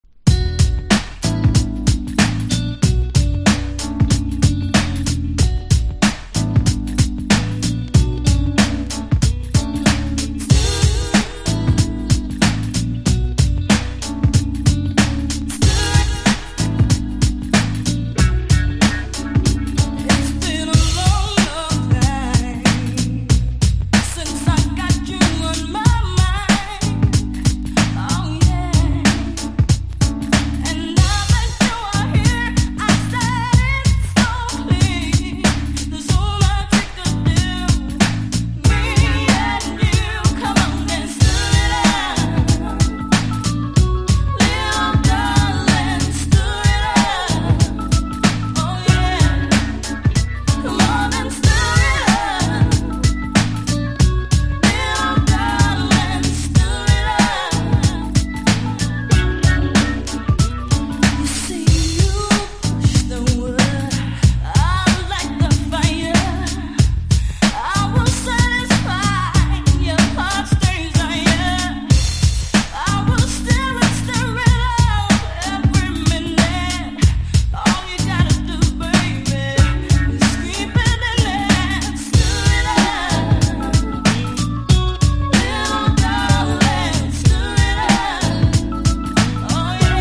（オリジナル盤）：REGGAE